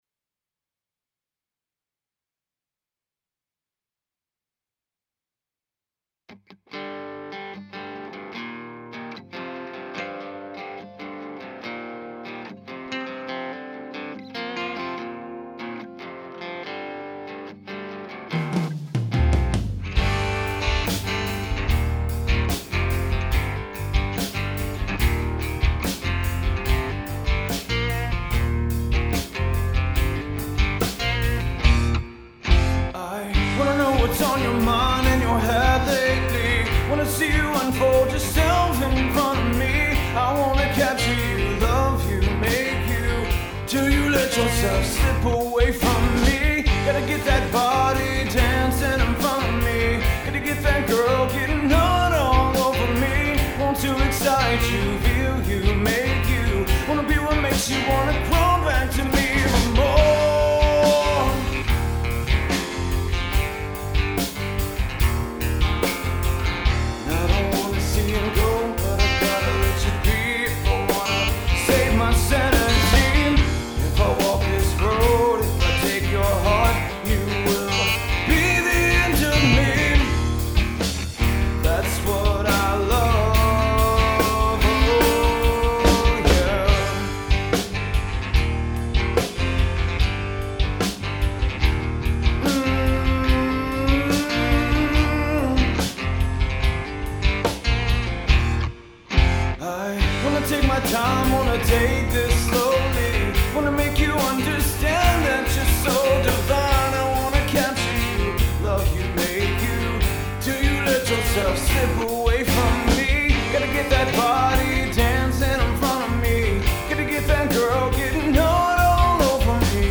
Hey all, give me your take on this mix. we are a 3 piece band with acoustic, bass, and drums. you are not going to hurt my feelings.